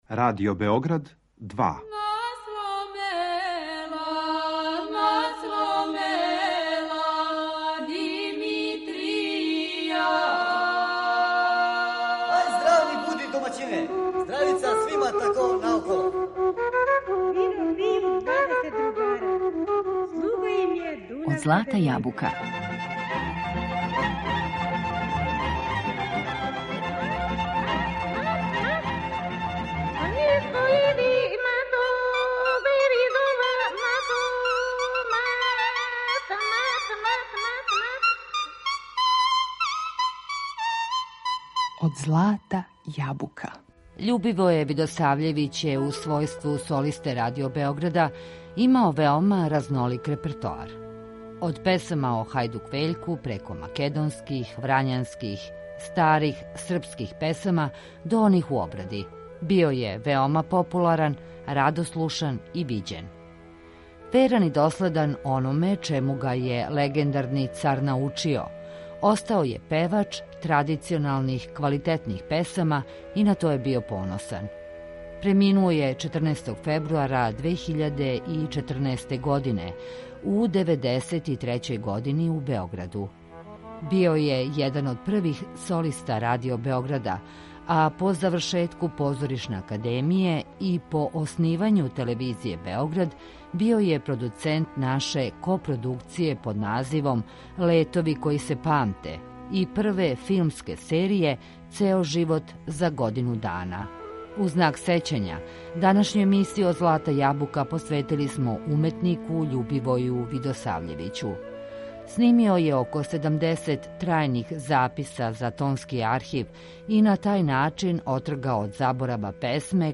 Снимио је више од седамдесет трајних записа за Тонски архив и на тај начин отргао од заборава многе лепе мелодије. Веран и доследан ономе чему га је легендарни Царевац научио, био је певач квалитетних традиционалних песама и на то је био поносан.